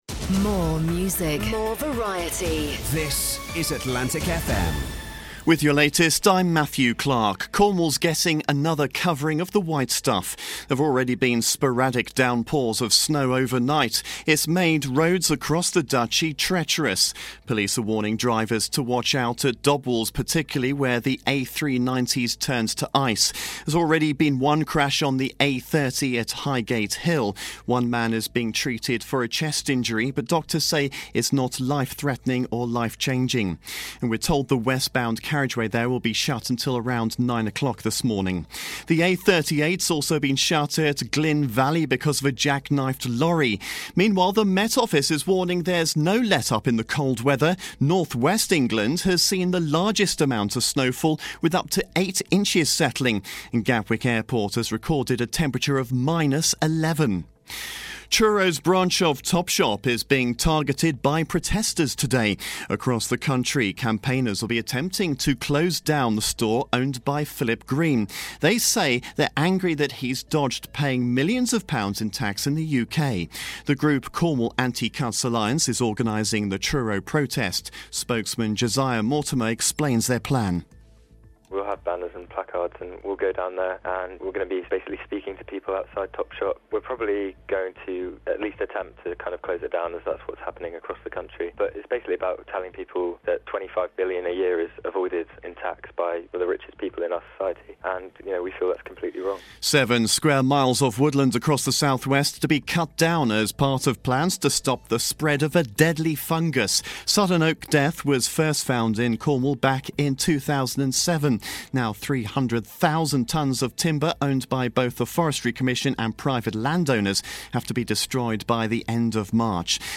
Atlantic FM bulletin 7am 18th Dec 2010